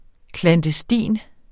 Udtale [ klandəˈstiˀn ]